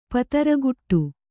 ♪ pataraguṭṭu